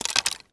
wood_tree_branch_move_05.wav